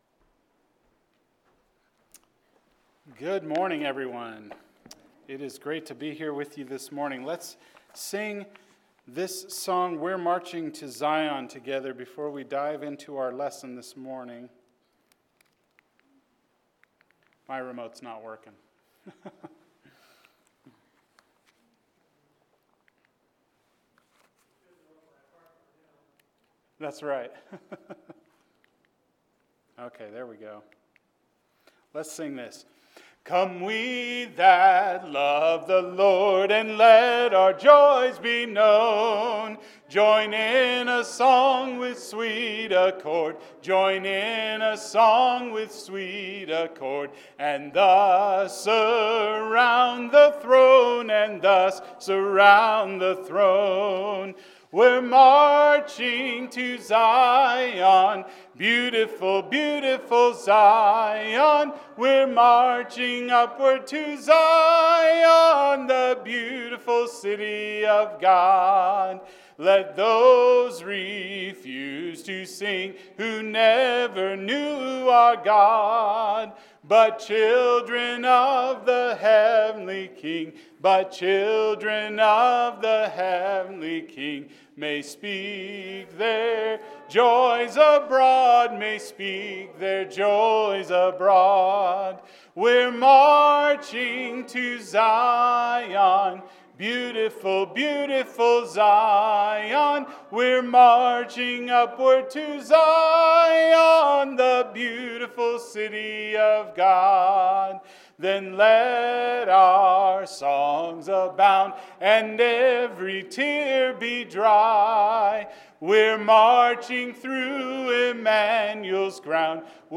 Great Crowds – Sermon